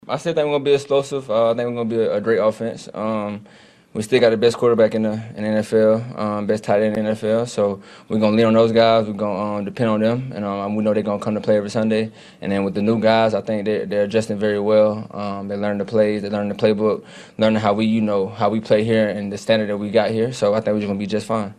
Receiver Mecole Hardman says they will be ok offensively.